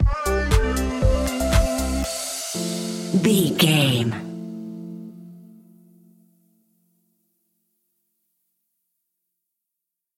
Ionian/Major
groovy
uplifting
energetic
bouncy
electric piano
synthesiser
drum machine
vocals
electronic music
synth leads
synth bass